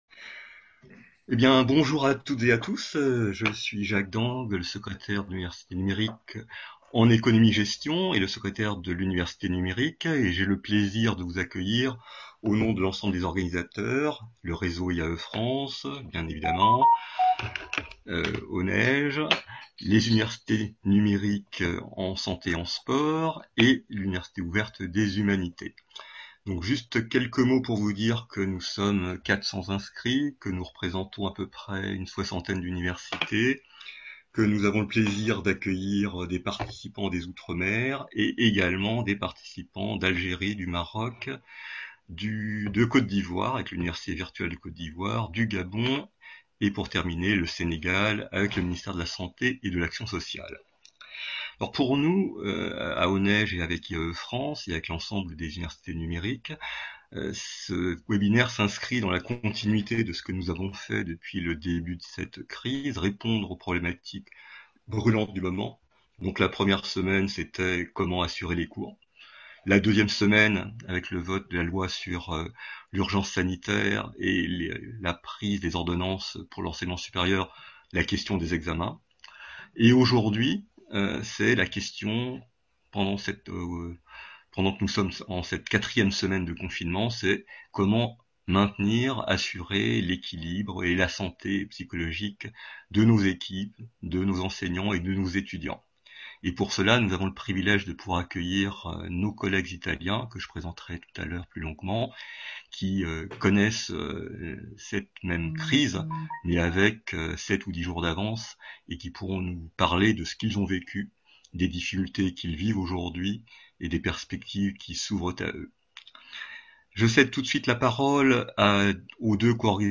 Webinaire – Regards croisés : motivation, équilibre et bien-être psychologique en période de crise | Canal U